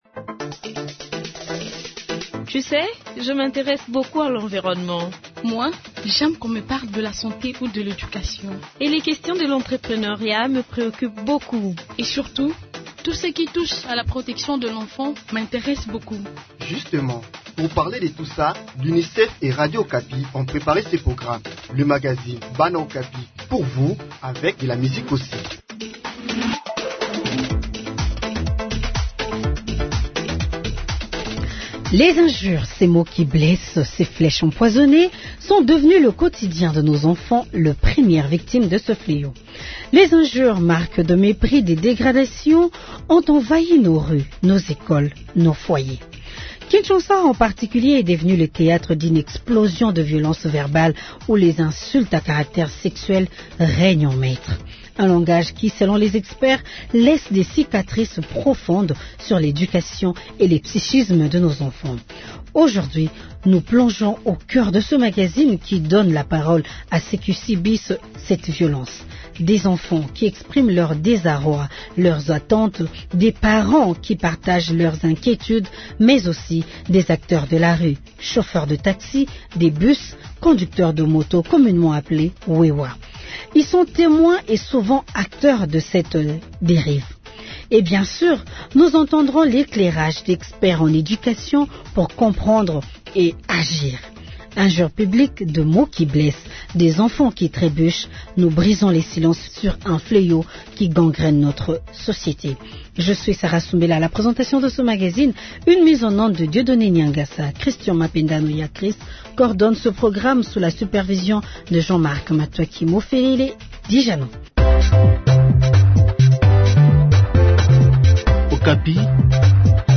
Aujourd’hui, nous plongeons au cœur de ce magazine, qui donne la parole à ceux qui subissent cette violence : des enfants, qui expriment leur désarroi et leurs attentes, des parents, qui partagent leur inquiétude, mais aussi des acteurs de la rue, chauffeurs de taxi, de bus, conducteurs de moto, communément appelés "wewa".
Et bien sûr, nous entendrons l'éclairage d'experts en éducation, pour comprendre et agir.